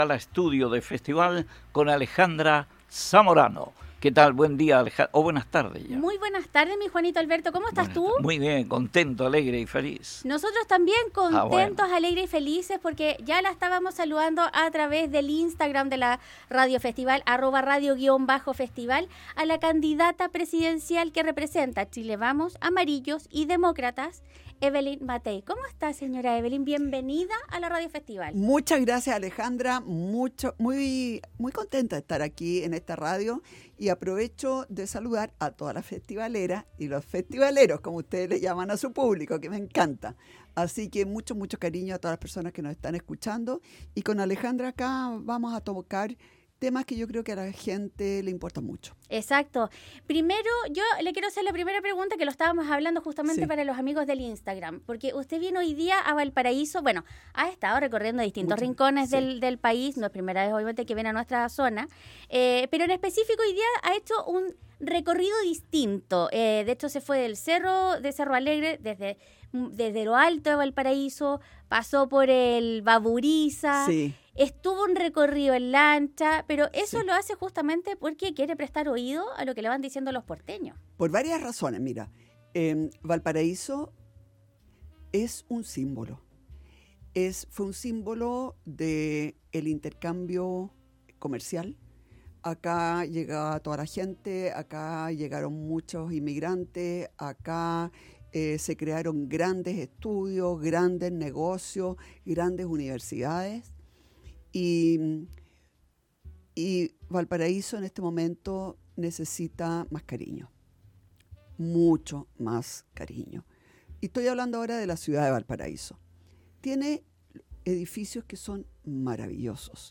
Visita a los estudios de Radio Festival Candidata Presidencial Evelyn Matthei